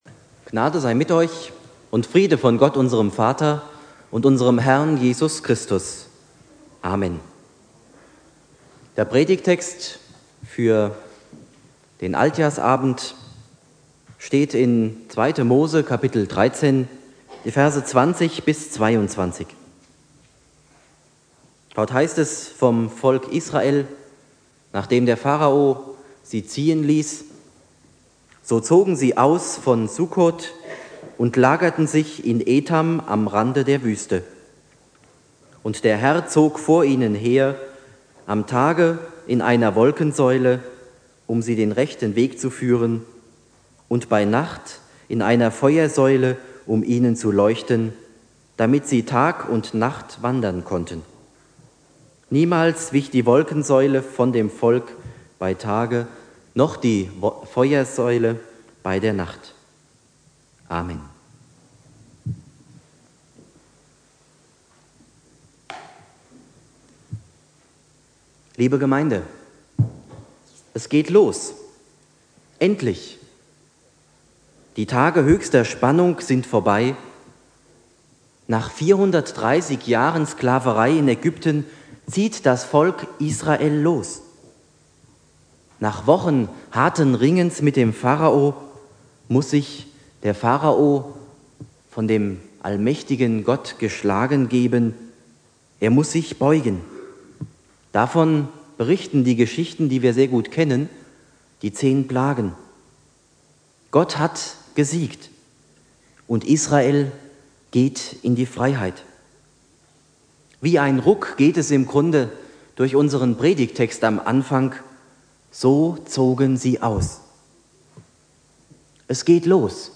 Predigt
Silvester